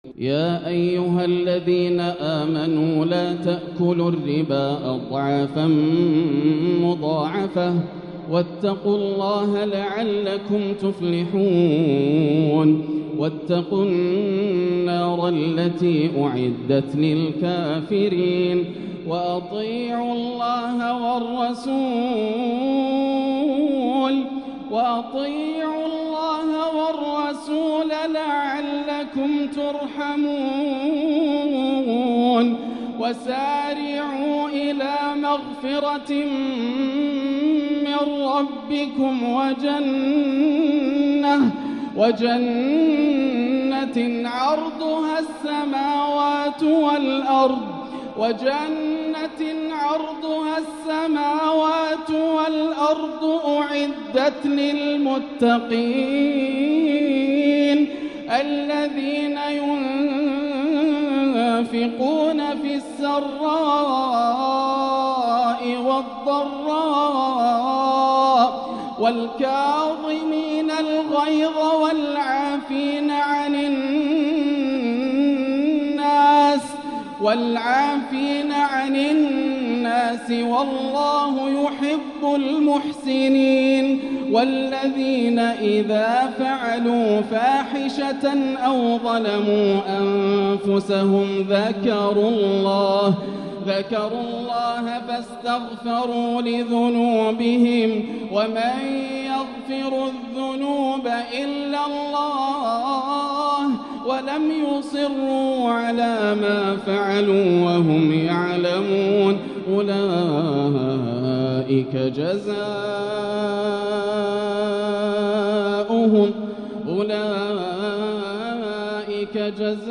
تحبير إبداعي بالصبا المؤثر > الروائع > رمضان 1445هـ > التراويح - تلاوات ياسر الدوسري